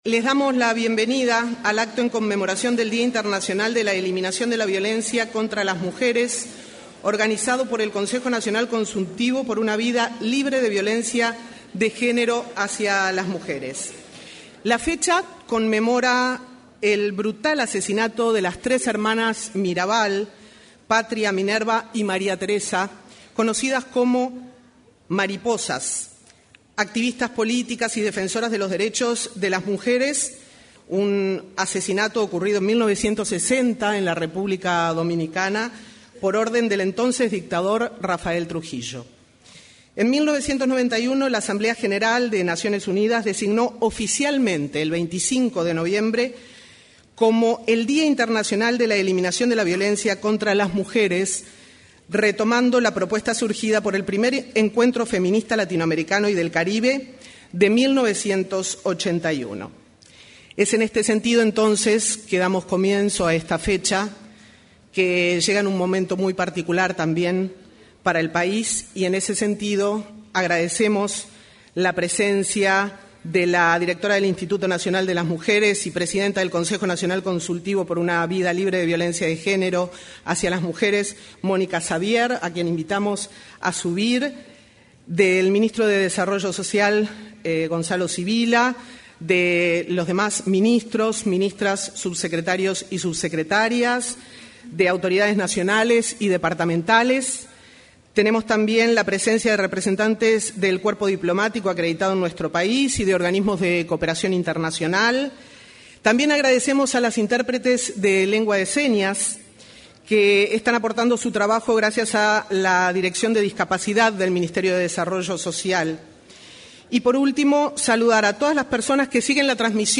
Conmemoración del Día Internacional de la Eliminación de la Violencia contra las Mujeres 25/11/2025 Compartir Facebook X Copiar enlace WhatsApp LinkedIn En el Día Internacional de la Eliminación de la Violencia contra las Mujeres, se expresaron la directora del Instituto Nacional de las Mujeres (Inmujeres), Mónica Xavier; el ministro del Interior, Carlos Negro, y la presidenta del Instituto del Niño y Adolescente del Uruguay (INAU), Claudia Romero.